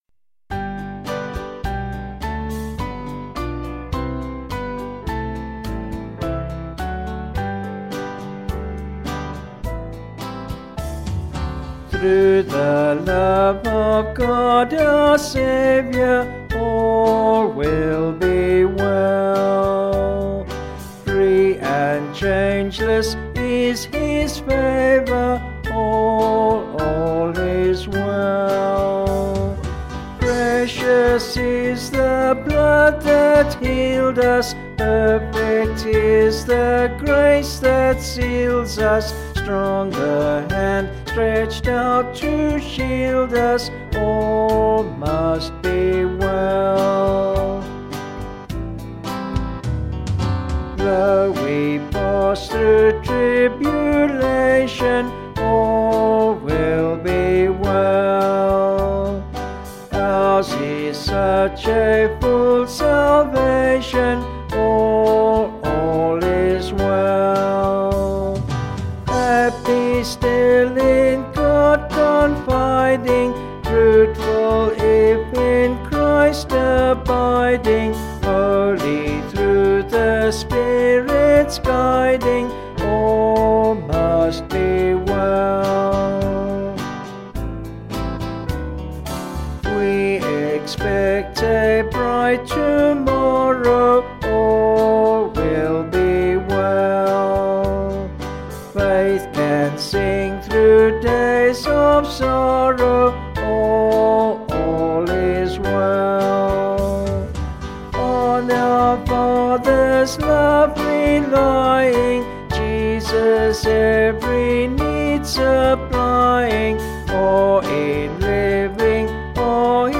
Vocals and Band   264.2kb Sung Lyrics